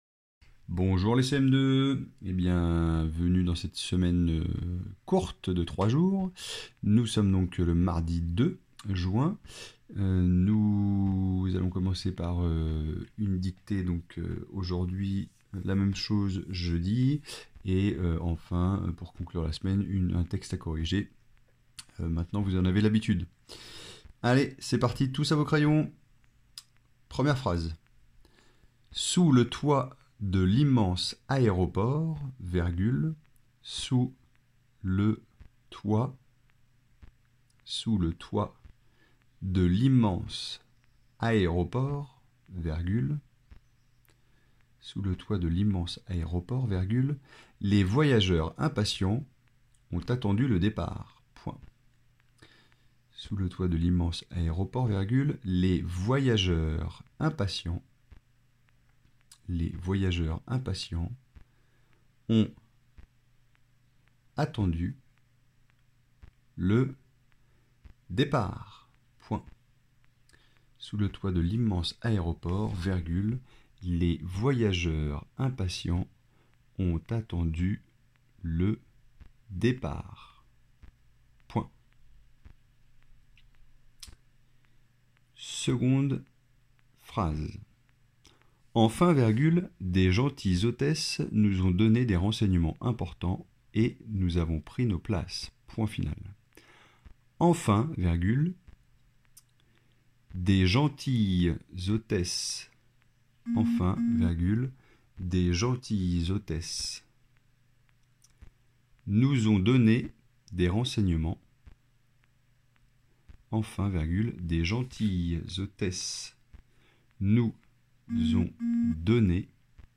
-Dictée :
dicti__BDe-mardi-2-juin.mp3